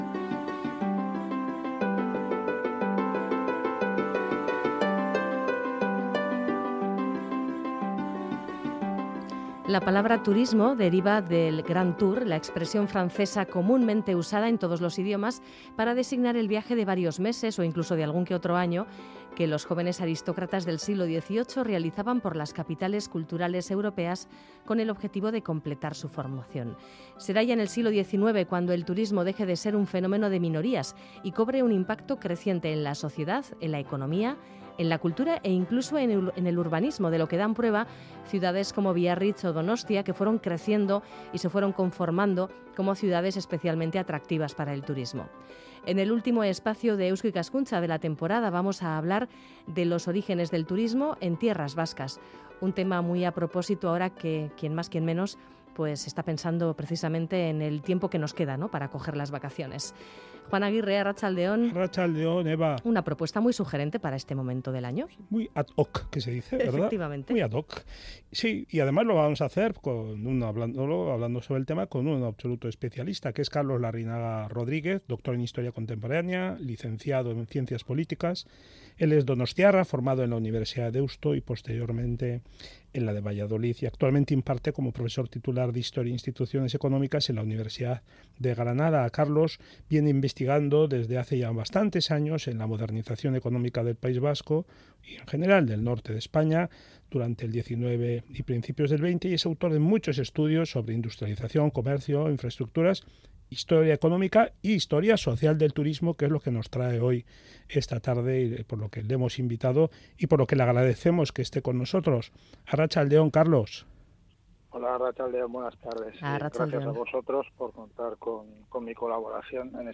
Entrevistamos a un especialista en la materia: